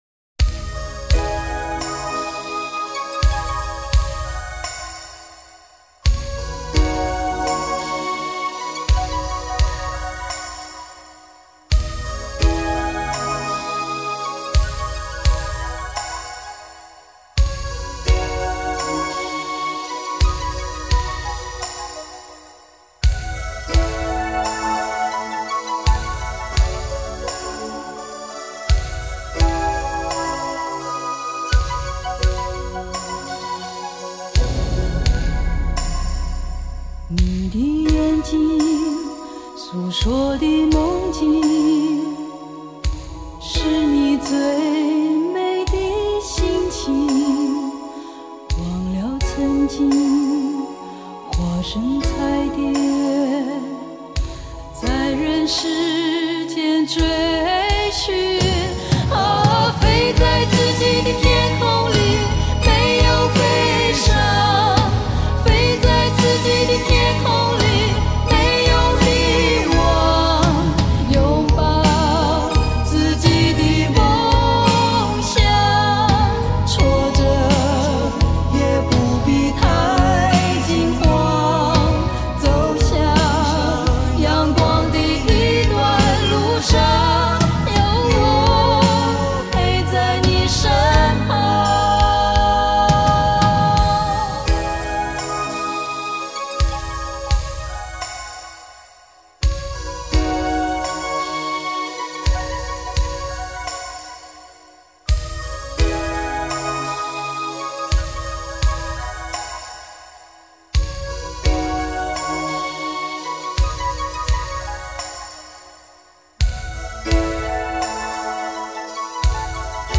流行歌坛第一位国台语无敌双女声